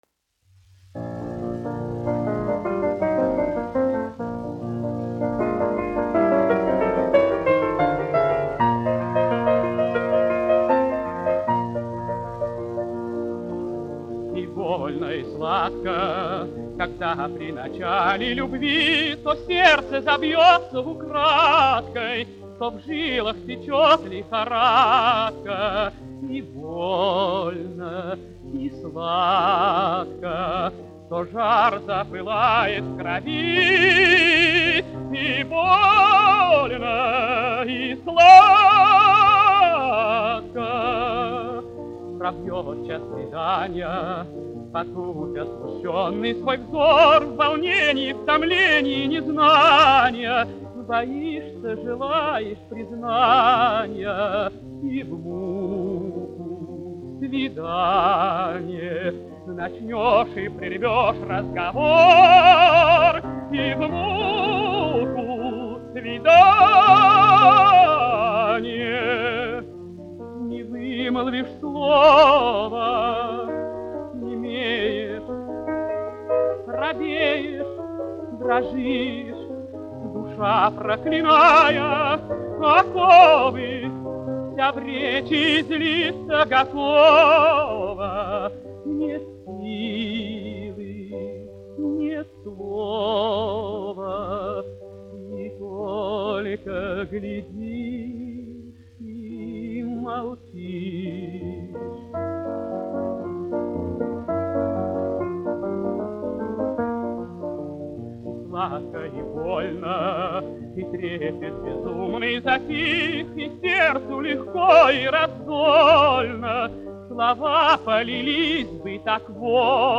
Лемешев, Сергей Яковлевич, 1902-1977, dziedātājs
1 skpl. : analogs, 78 apgr/min, mono ; 25 cm
Dziesmas (augsta balss) ar klavierēm
Skaņuplate
Latvijas vēsturiskie šellaka skaņuplašu ieraksti (Kolekcija)